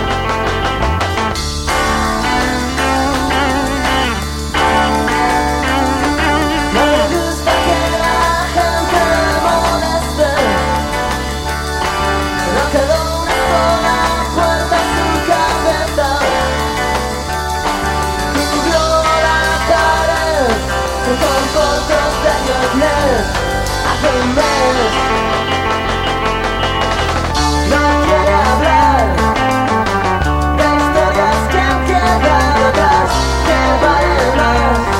# Psychedelic